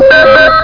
PHONE5.mp3